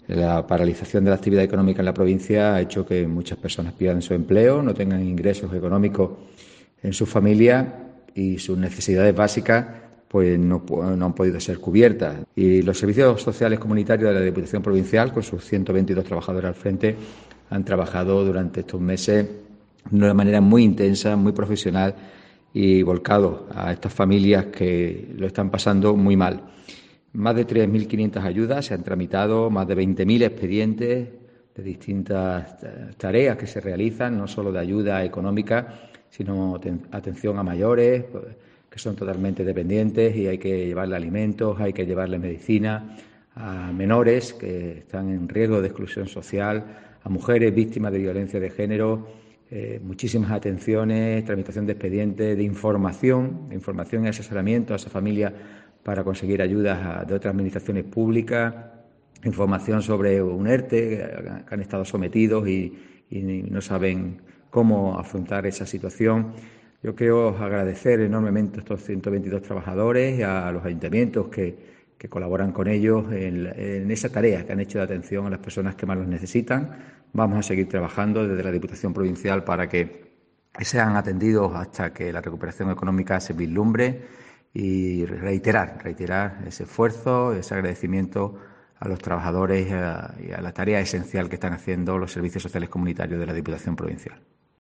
Escucha aquí al presidente de Diputación, Francisco Salado.